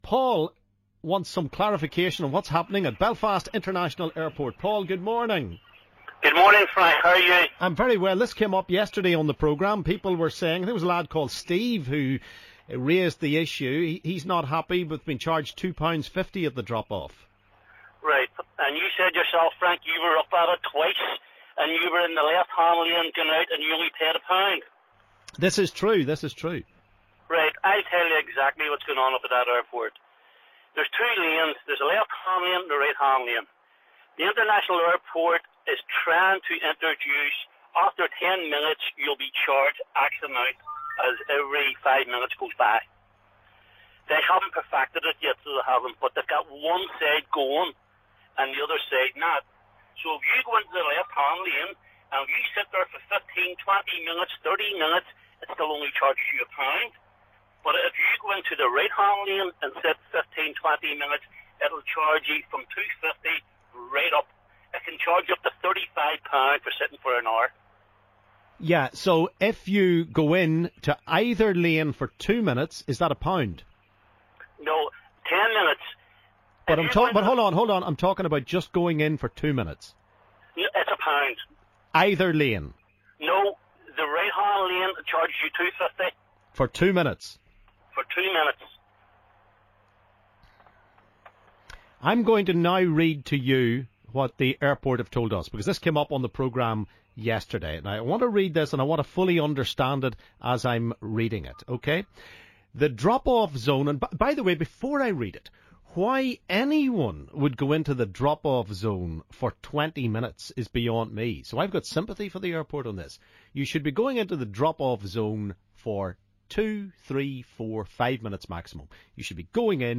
LISTEN ¦ Callers confused over airport 'drop-off' and 'pick-up' zones